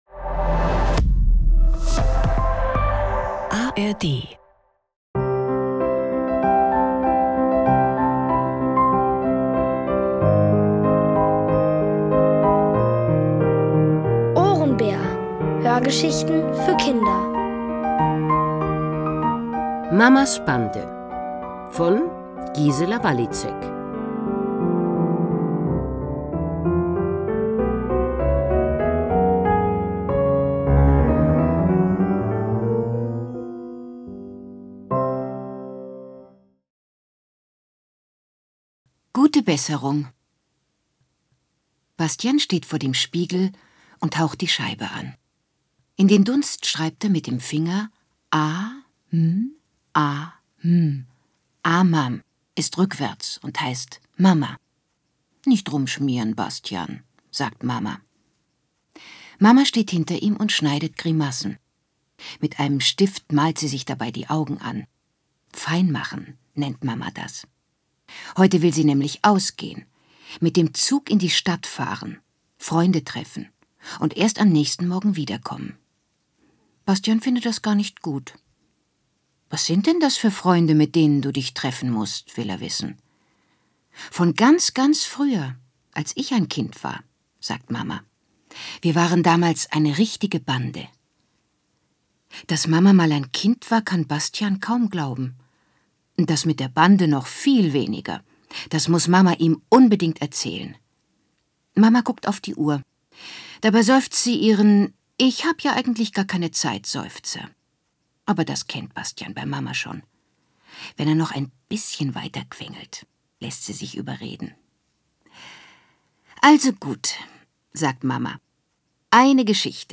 Mamas Bande | Die komplette Hörgeschichte! ~ Ohrenbär Podcast